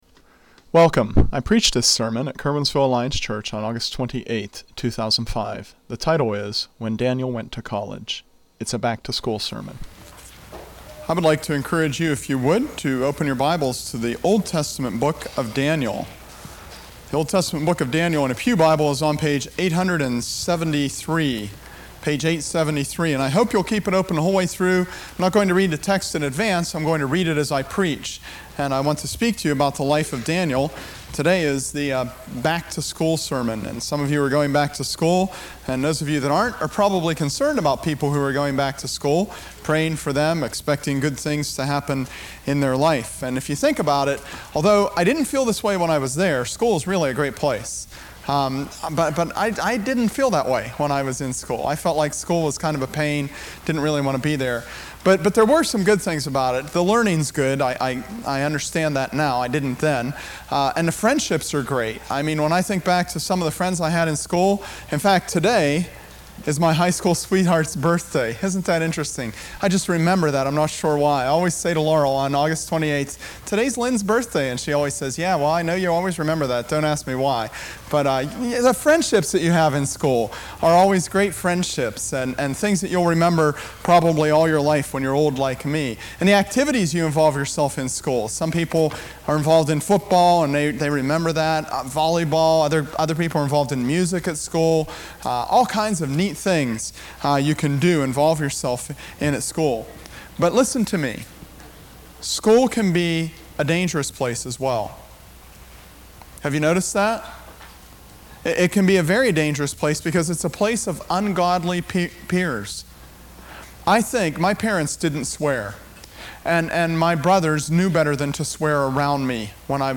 Back to School Sermon…